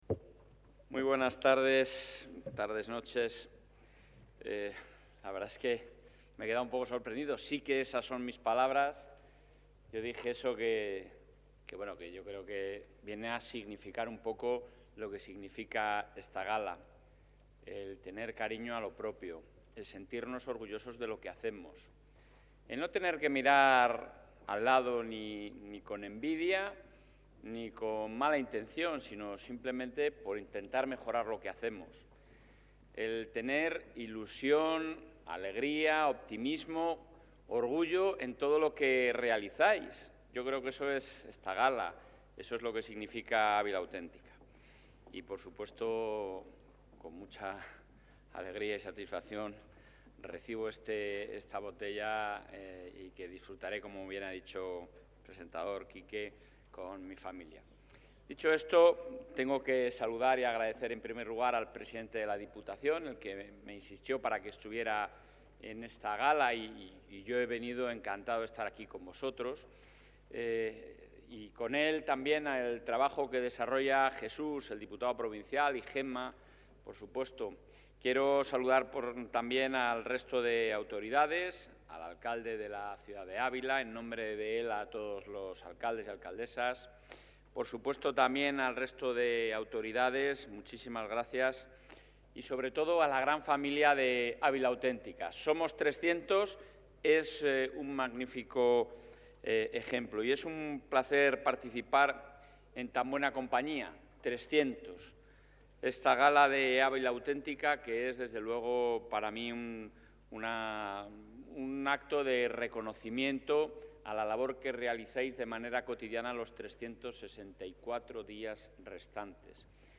En la Gala Ávila Auténtica Somos 300, el presidente de la Junta de Castilla y León, Alfonso Fernández Mañueco, ha...
Intervención del presidente.